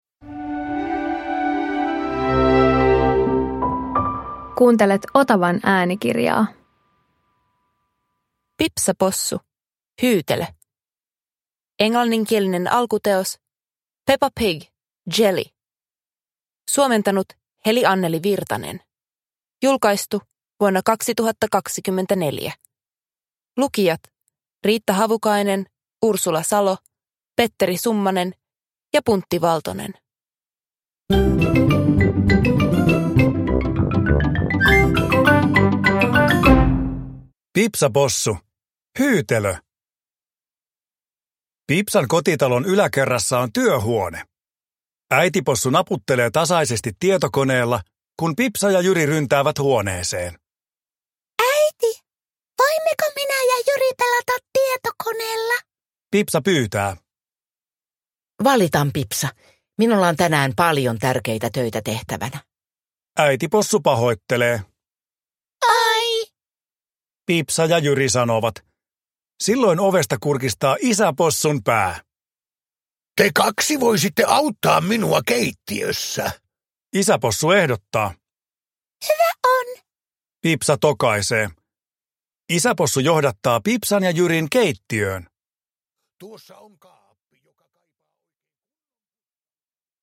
Pipsa Possu - Hyytelö – Ljudbok